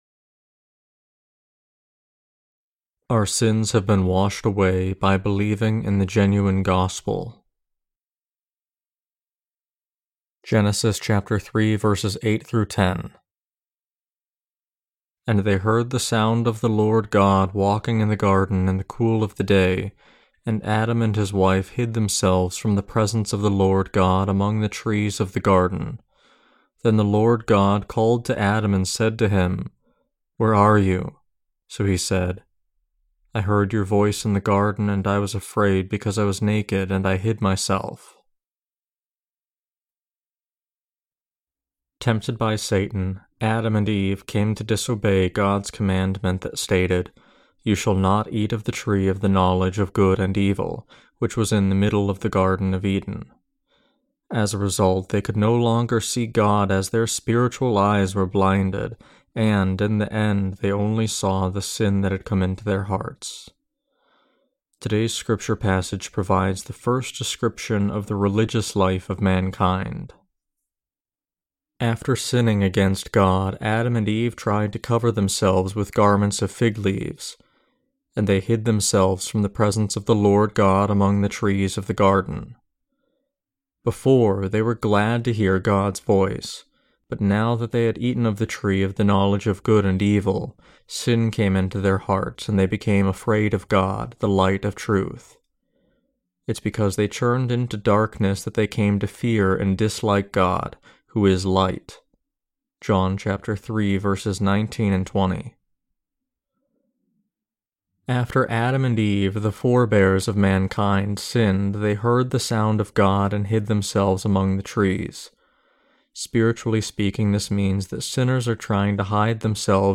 Sermons on Genesis (II) - The Fall of Man and The Perfect Salvation of God Ch3-8.